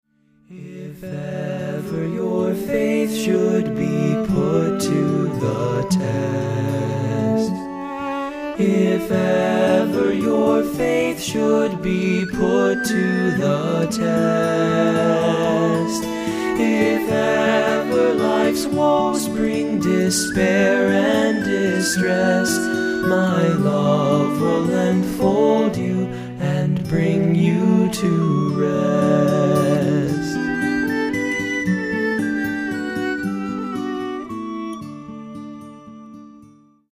Music Category:      Christian